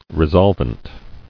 [re·sol·vent]